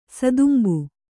♪ sadumbu